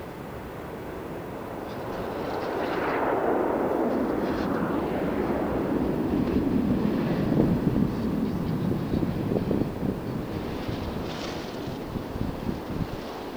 airsound1.mp3